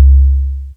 001 Kick Power trunc2.wav